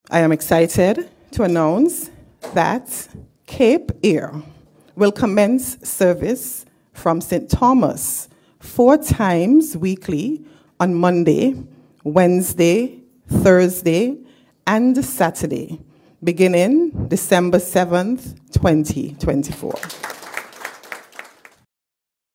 Minister of Tourism for St. Kitts, the Hon. Marsha Henderson, speaking at press conference convened by the Ministry of Tourism, on Oct. 31st.